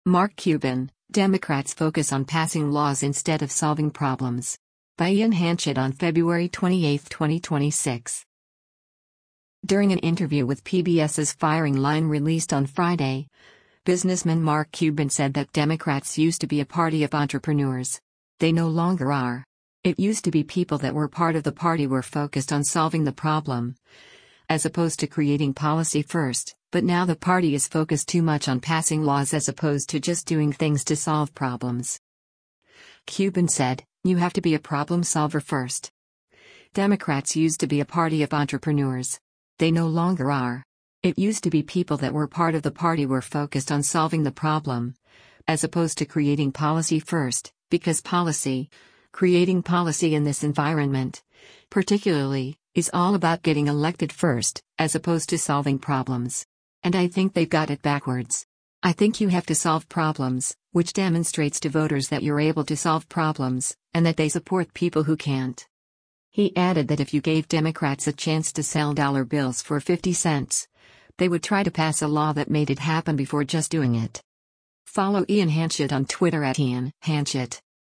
During an interview with PBS’s “Firing Line” released on Friday, businessman Mark Cuban said that “Democrats used to be a party of entrepreneurs. They no longer are. It used to be people that were part of the party were focused on solving the problem, as opposed to creating policy first,” but now the party is focused too much on passing laws as opposed to just doing things to solve problems.